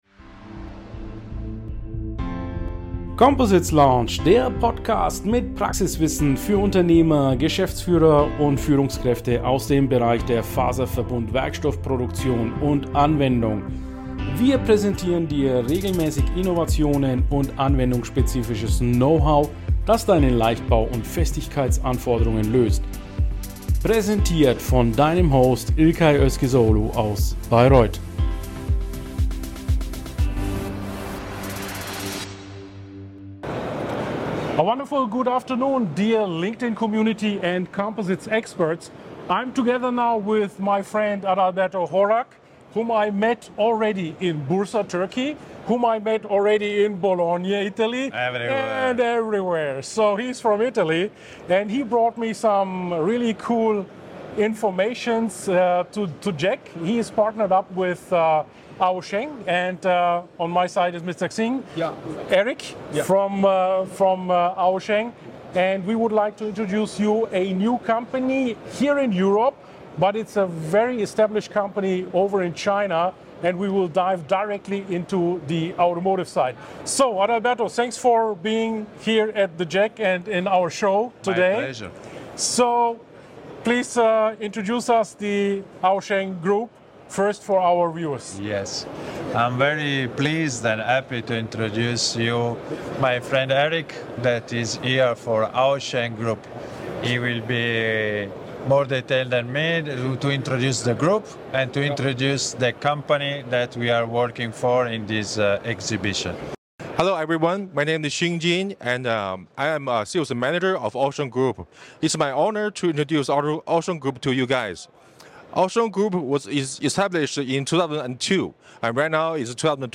#131 Interview with AOSHENG during JEC World 2024